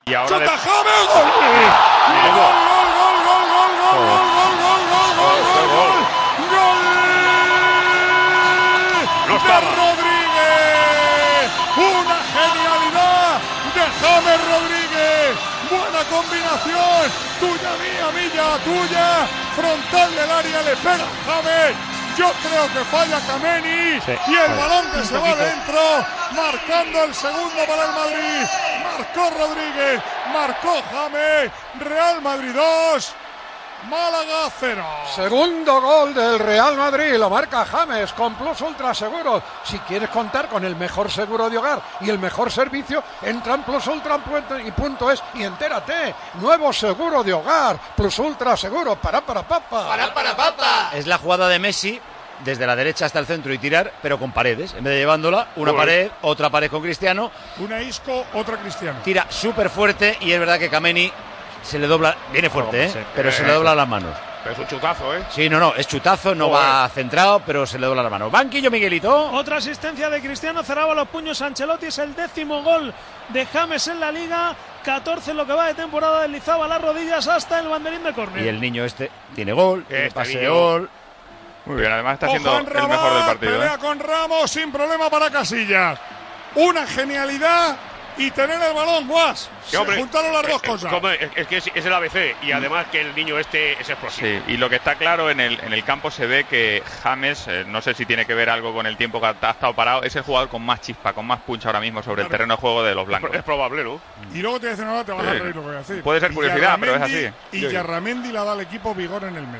Transmissió del partit de la lliga de futbol masculí entre el Real Madrid i el Málaga.
Narració del gol de James Rodríguez per al Real Madrid, publicitat i valoració.
Esportiu